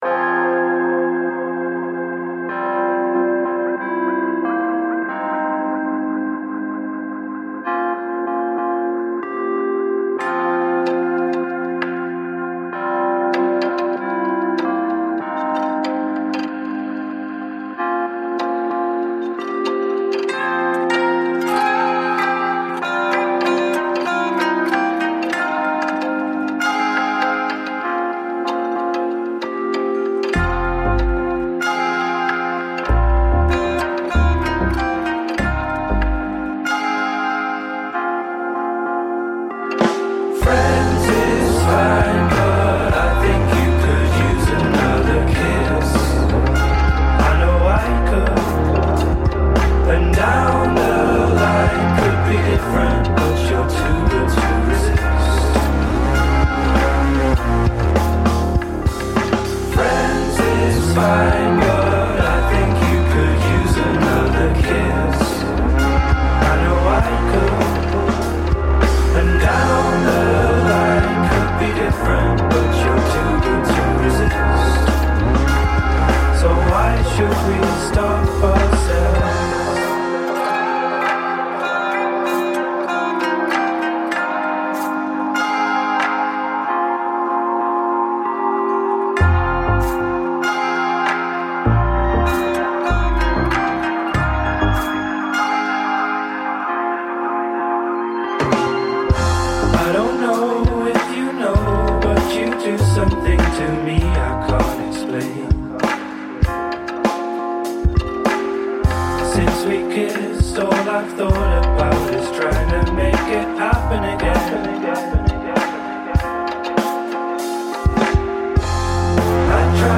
electronic outfit